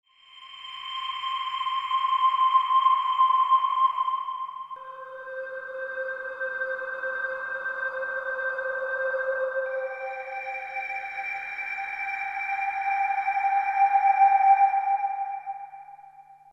Джинглы,телезаставки начала 2000-х
На мой взгляд пэд в этом примере состоит из струнных (бас + верхние ноты) + пианино (каждый такт басовая нота) В целом вроде бы стандартные звуки, которые есть на многих синтезаторах (мой пример сделан на Roland JV2080).